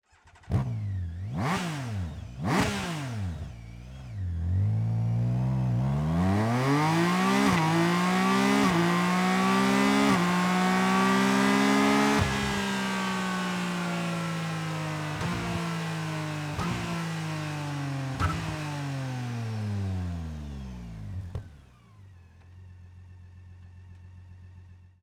Akrapovic Slip-On Line (Titanium) Endschalldämpfer mit Titan-Hülle und Carbon-Endkappe, mit EU-Zulassung; für
Damit lange Touren auch ein faszinierendes Sound-Erlebnis werden, haben die Akrapovic Ingenieure mit diesem Slip-On System eine weitere Soundverbesserung für diesen exklusiven Sechs-Ventil-Motor entwickelt.